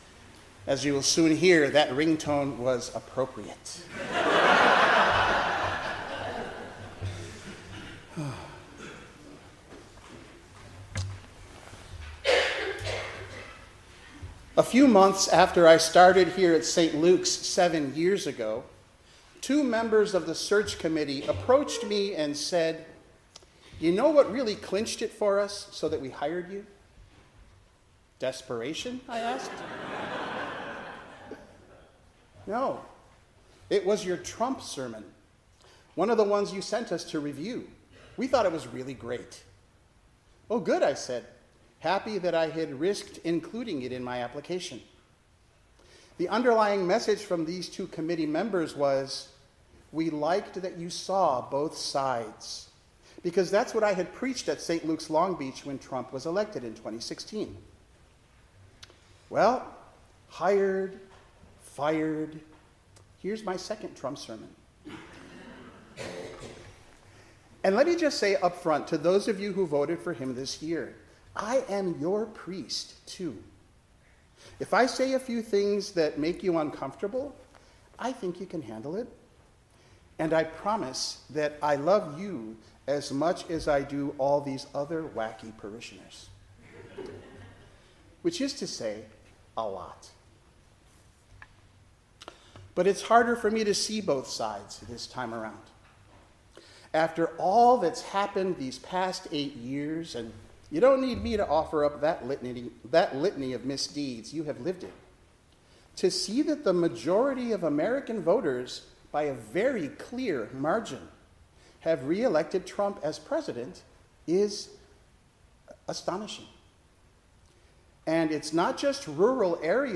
Preacher
Service Type: 10:00 am Service